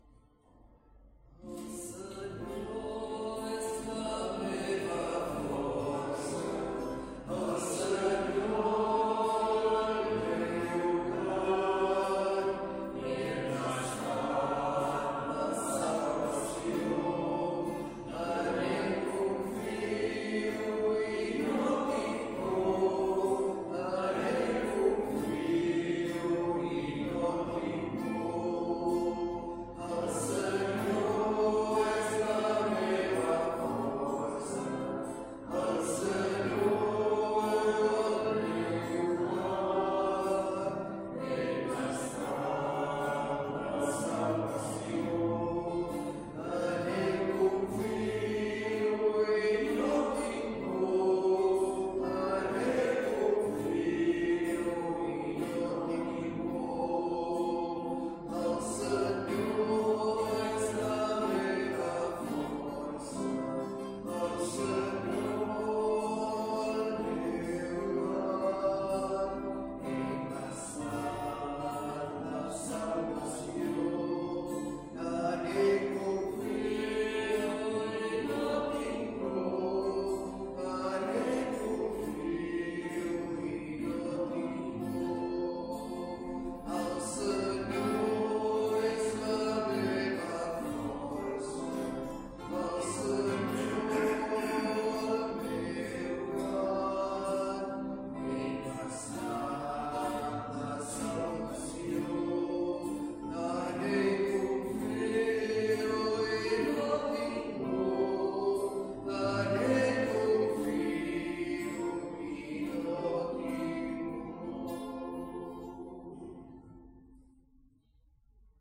Església del Sagrat Cor - Diumenge 29 de juny de 2025
Vàrem cantar...